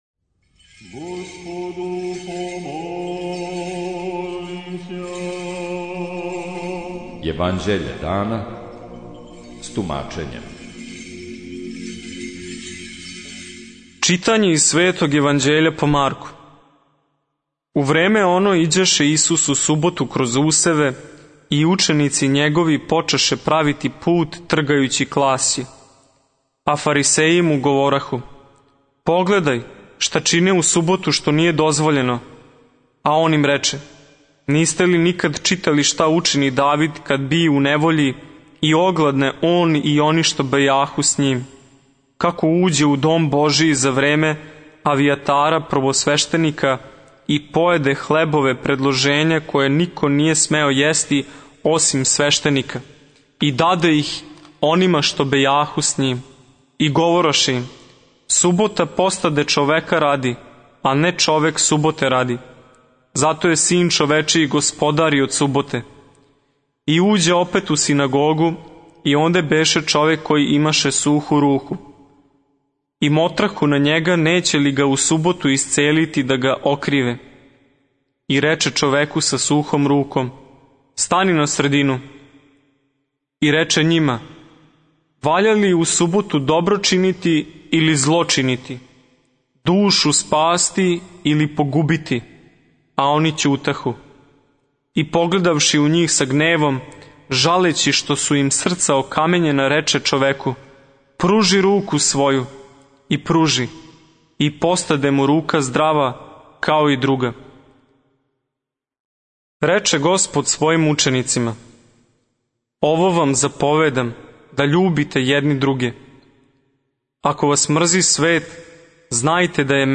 Читање Светог Јеванђеља по Марку за дан 21.01.2020. Зачало 50.